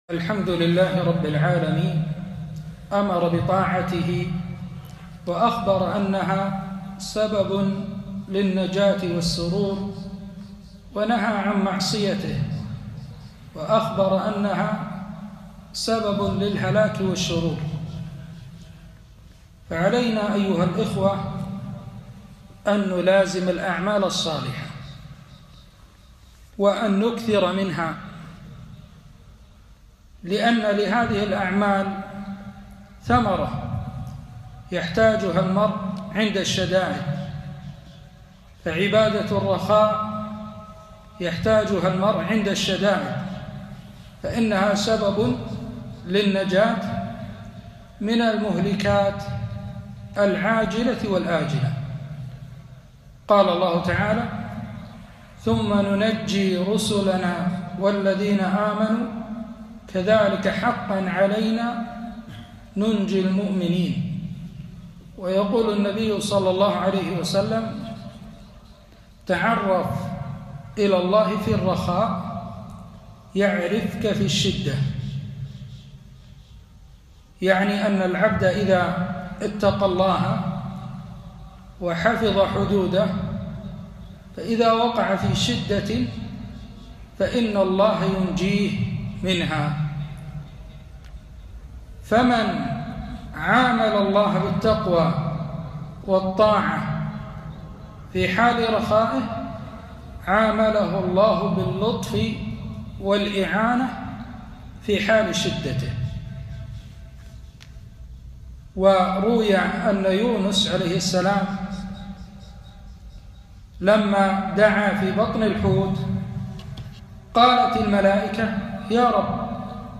كلمة - تعرف إلى الله في الرخاء يعرفك في الشدة